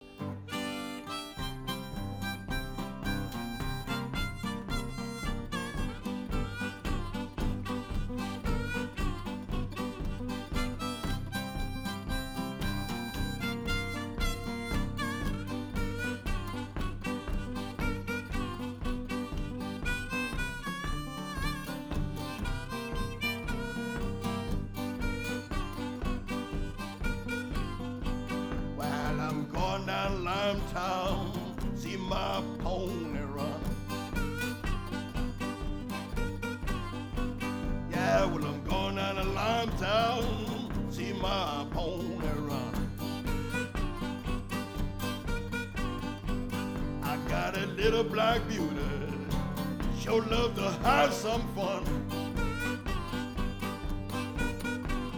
Live recording
Blues
recorded with Soundfield MKV in 200 yo "Dutch" style barn
Ambisonic order: F (4 ch) 1st order 3D
Microphone name: Soundfield MKV
Array type: tetrahedral
Capsule type: subcardiod